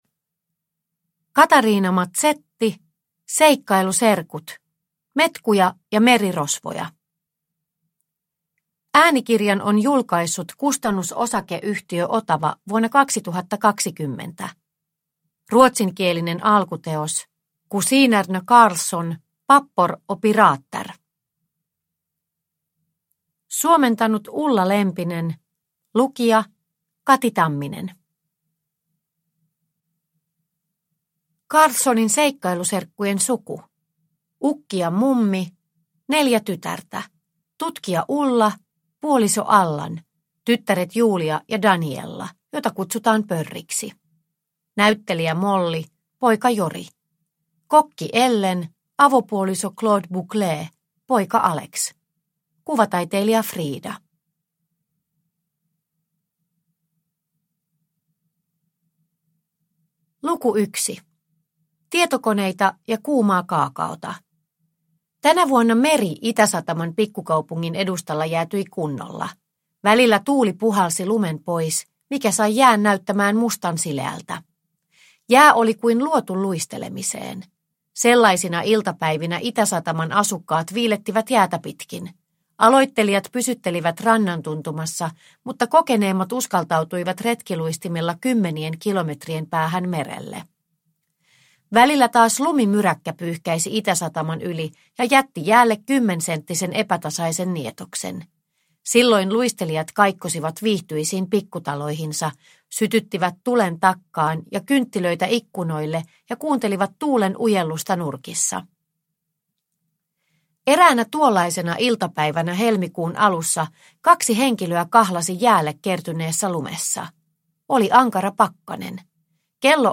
Metkuja ja merirosvoja – Ljudbok – Laddas ner